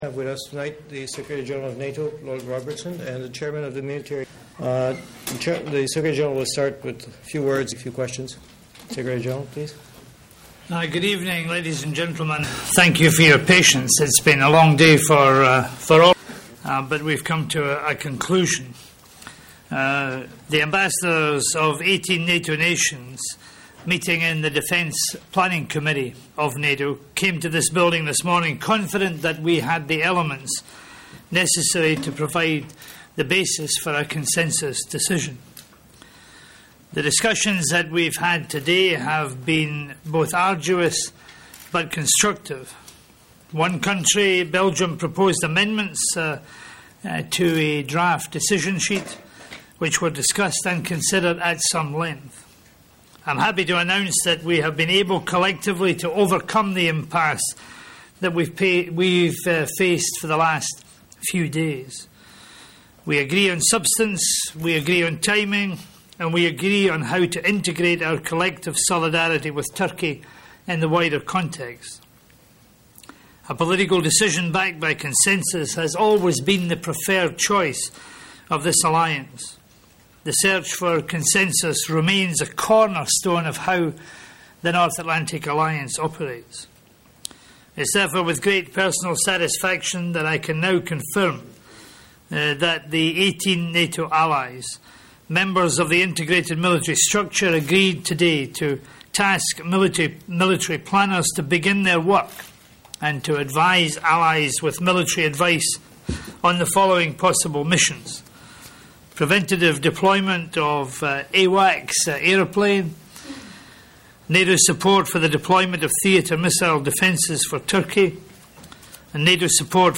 Press conference
by NATO Secretary General, Lord Robertson after the NATO Defence Planning Committee Meeting